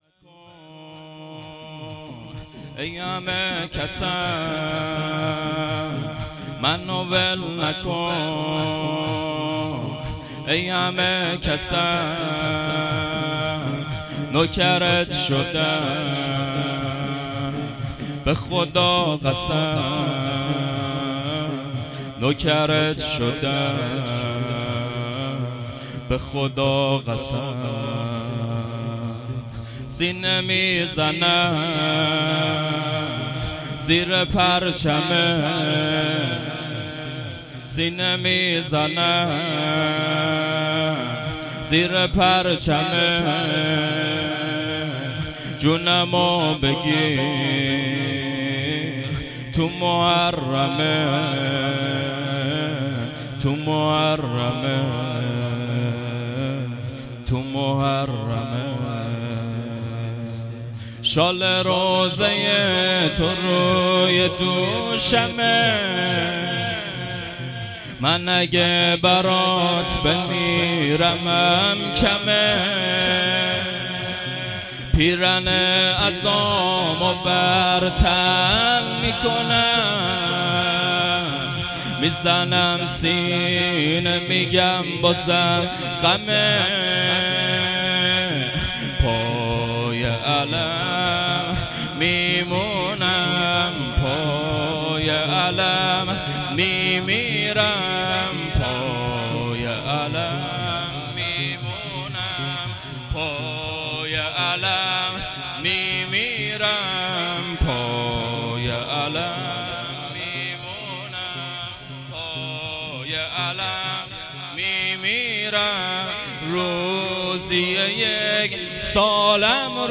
شور پایانی ای همه کسم به خودت قسم